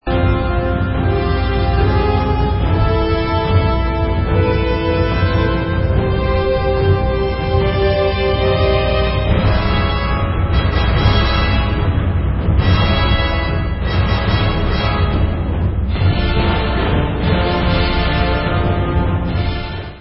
ve studiu Abbey Road